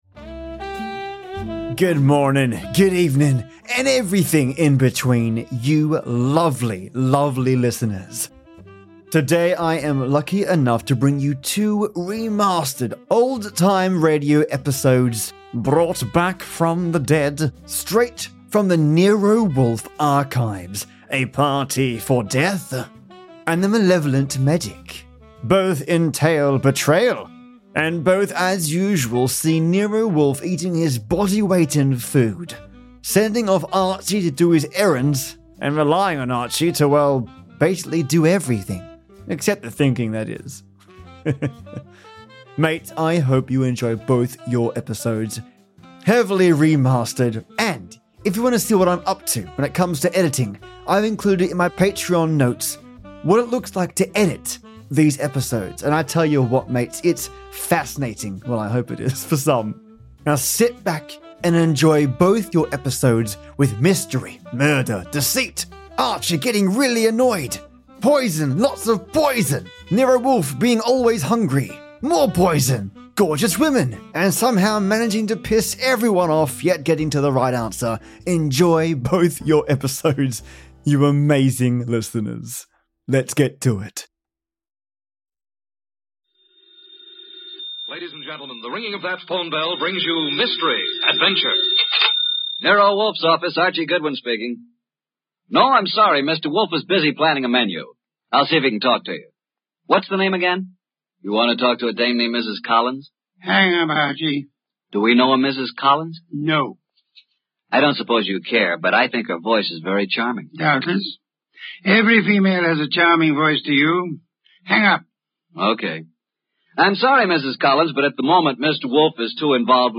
Old Time Radio - Party Of Death And The Malevolant Medic STANDARD